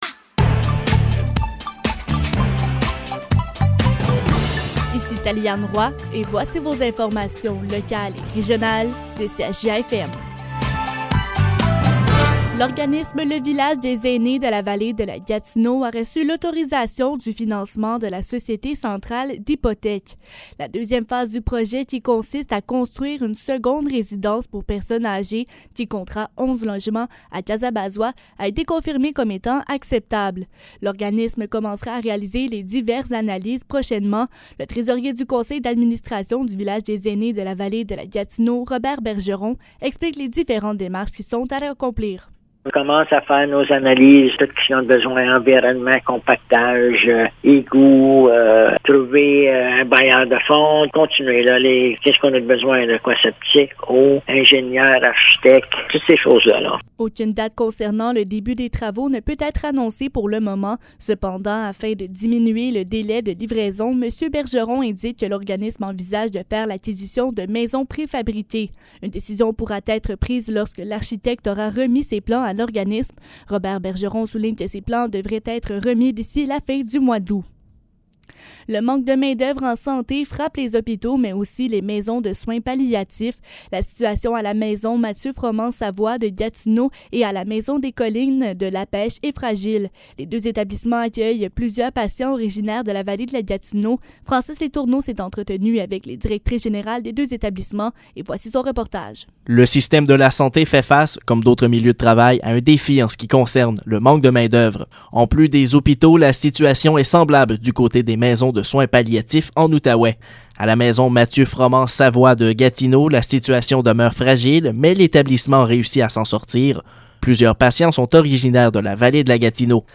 Nouvelles locales - 17 août 2022 - 12 h